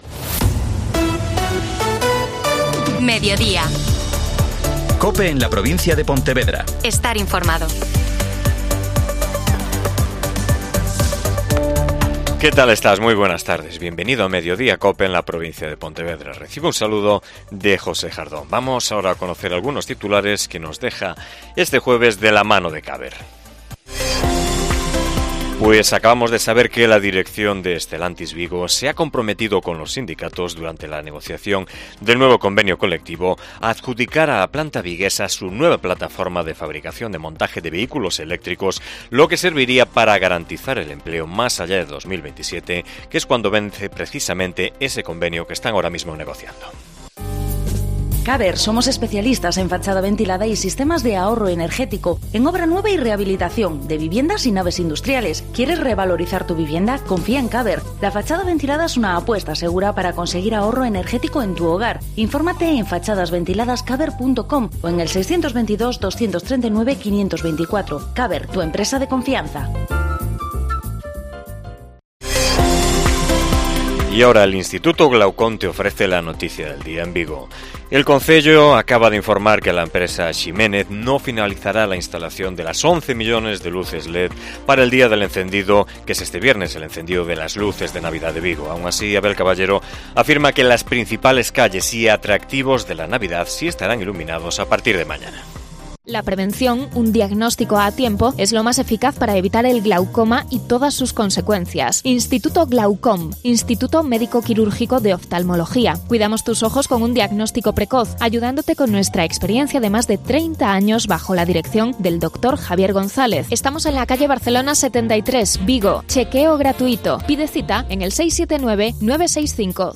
AUDIO: Magazine provincial